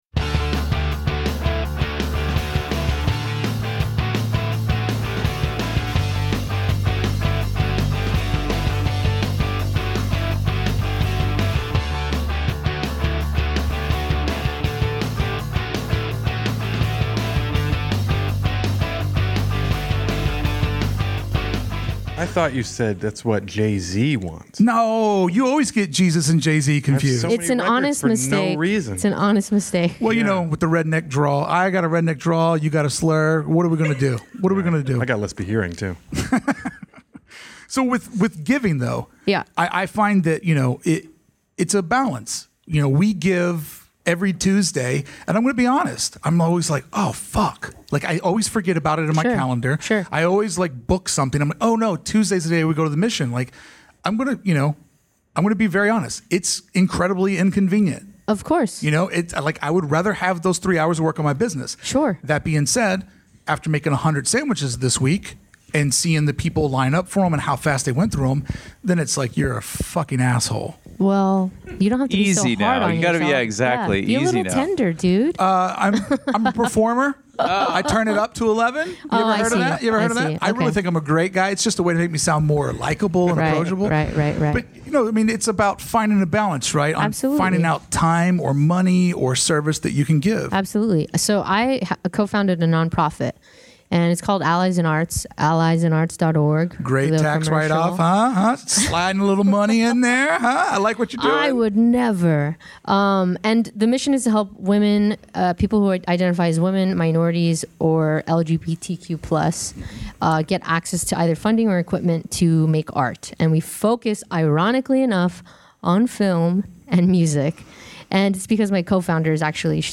AID Live from Los Angeles installment